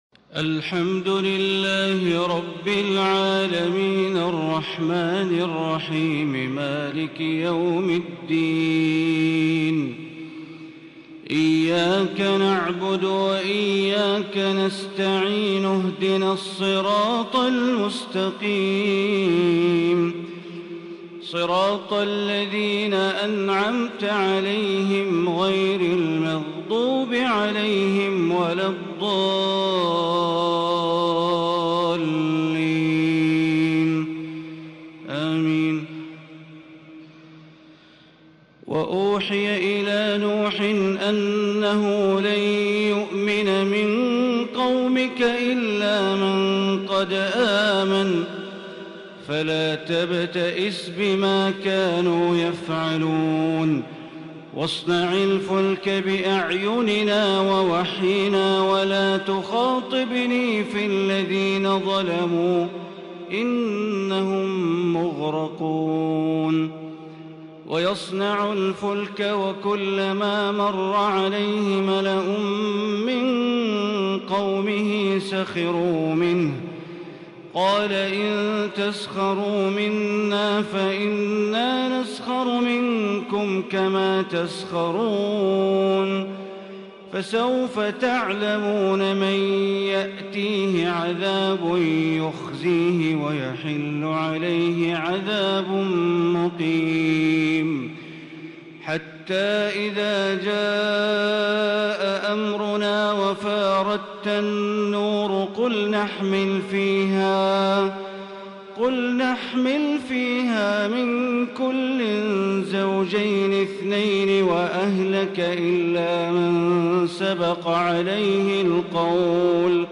أجمل الليالي للشيخ بندر بليلة يتلو بإتقان ويتغنّى بالقرآن | سورة هود 36 لآخرها > تراويح ١٤٤٢ > التراويح - تلاوات بندر بليلة